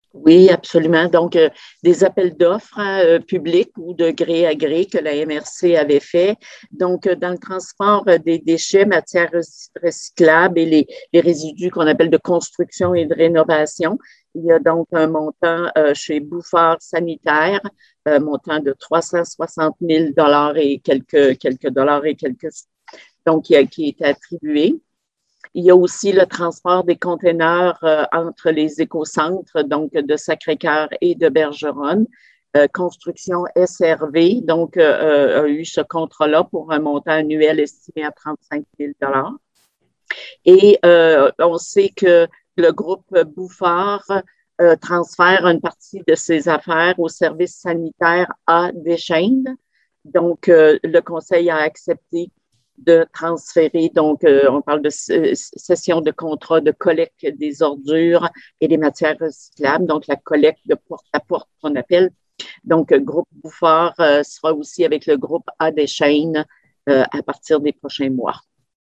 La préfet de la MRC et mairesse de Forestville, madame Micheline Anctil, a acceptée de nous faire un résumé de la séance en détaillant les points importants de la rencontre.